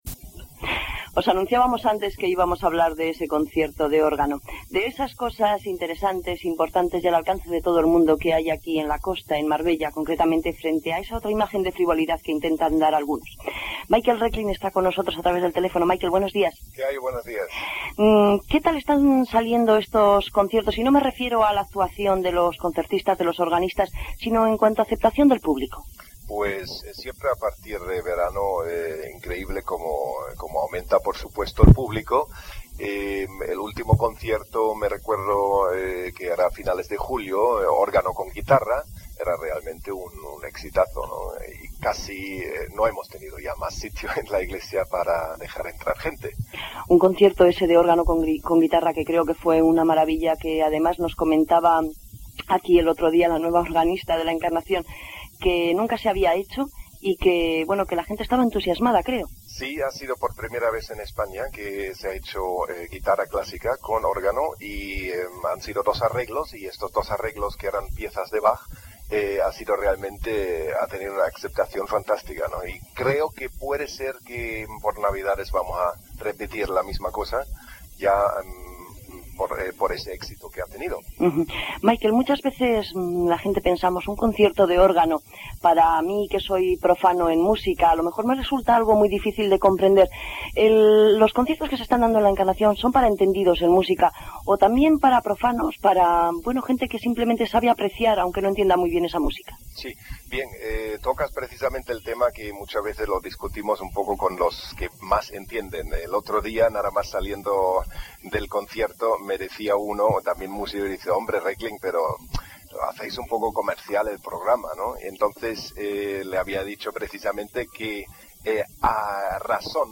Escuchar la gran sonoridad del �rgano del Sol Mayor.